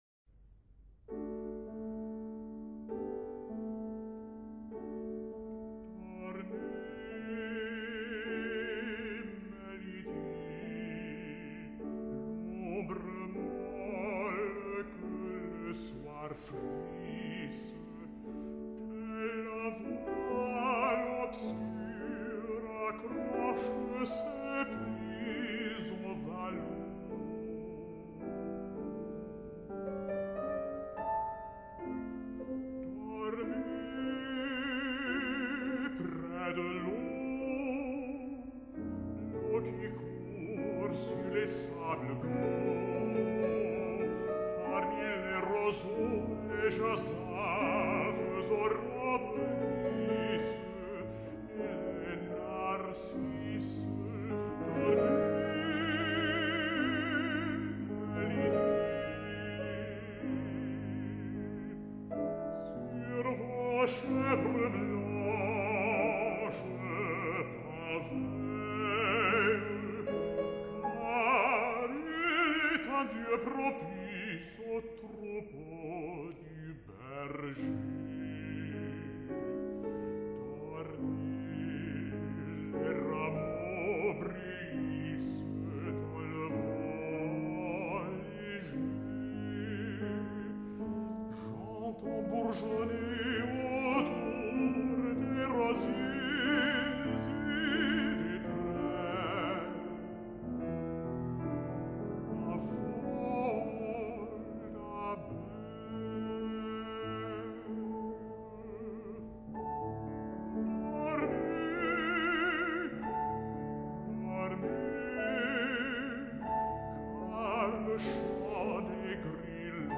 Baritone
Piano